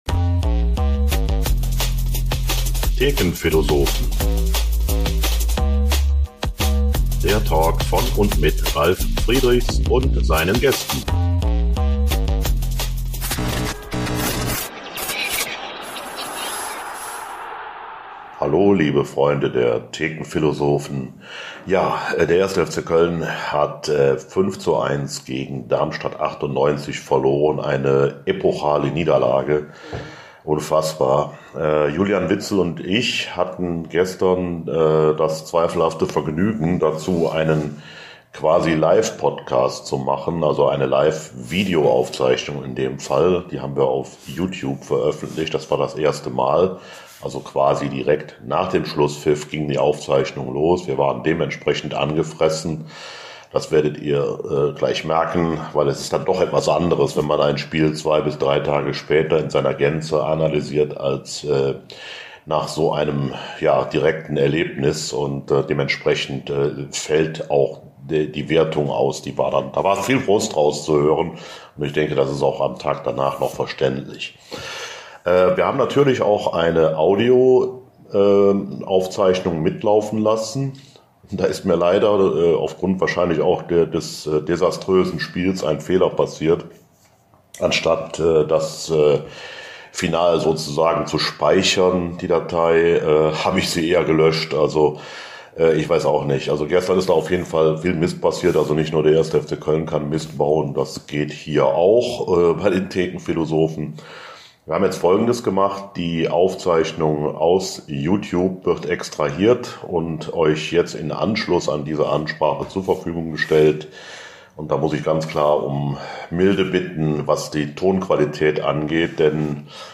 Hier mit etwas Verzögerung nun die Audio-Version (technisch nicht perfekt) eines sehr emotionalen Gesprächs von zwei tief enttäuschten und angesäuerten FC-Fans.